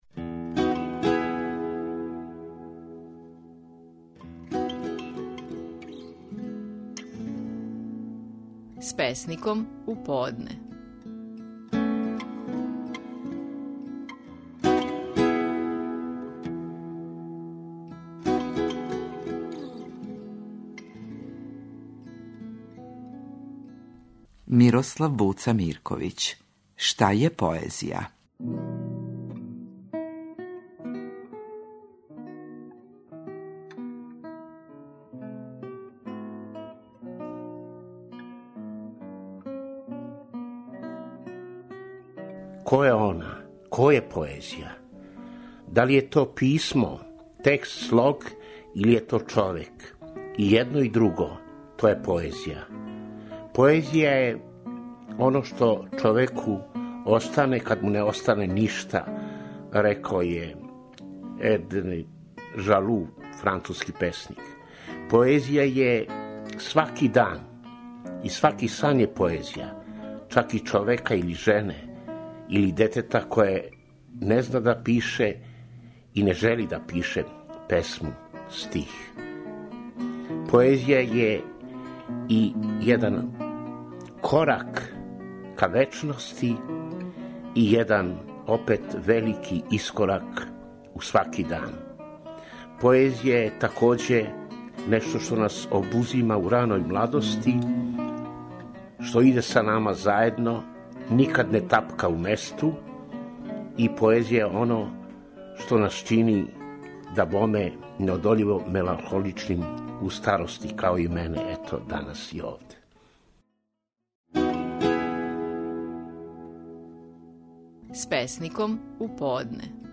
Стихови наших најпознатијих песника, у интерпретацији аутора.